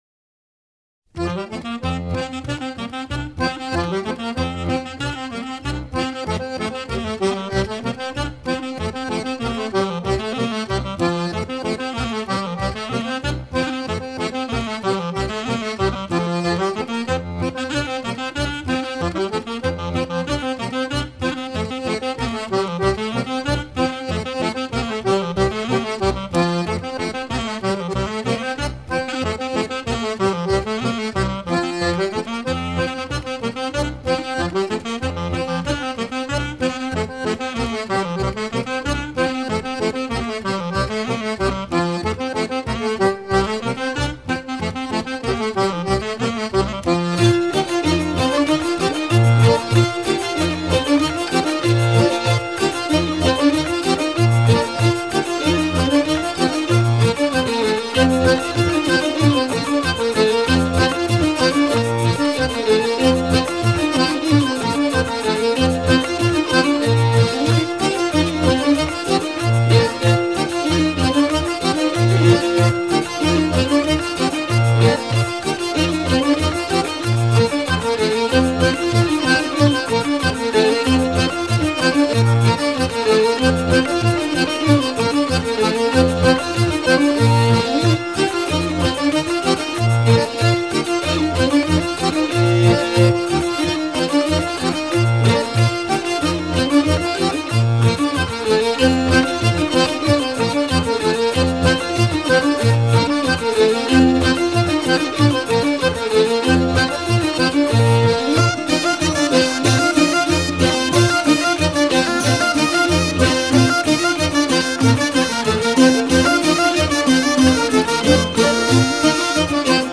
danse : rond
Pièce musicale éditée